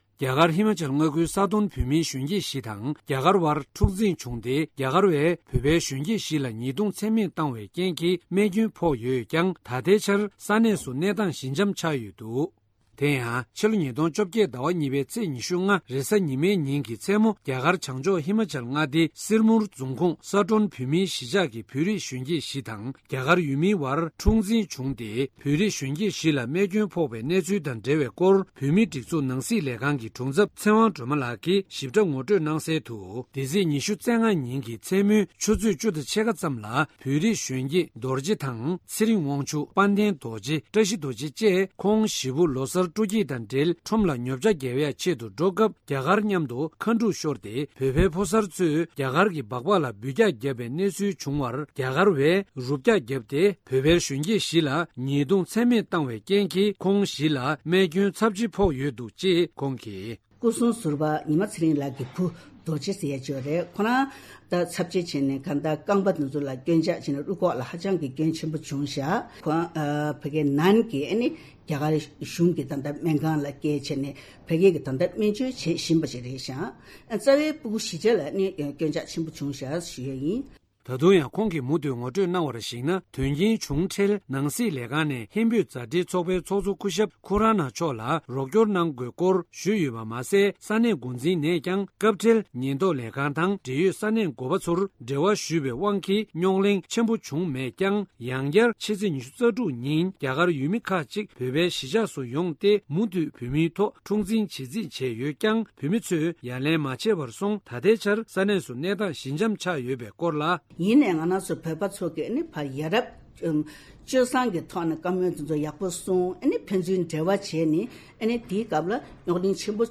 སྒྲ་ལྡན་གསར་འགྱུར།
གསར་འགོད་པ